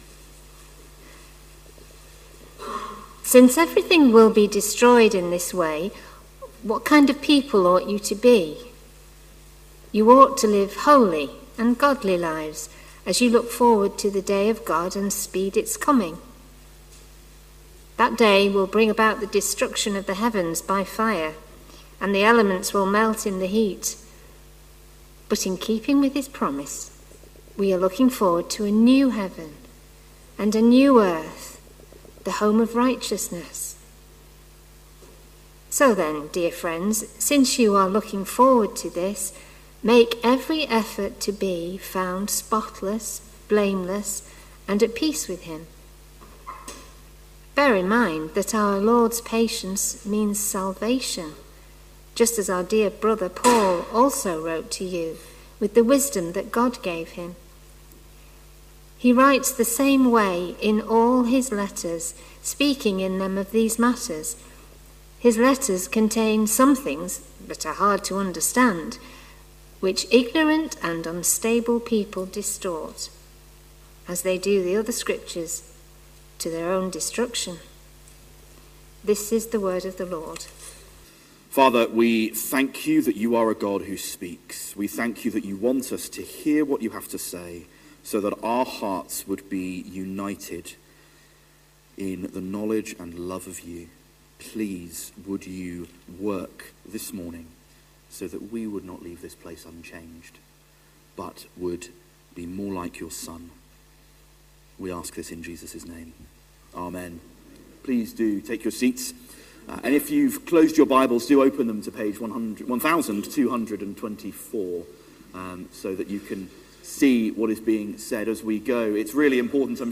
Talk starts with prayer after reading at 1.18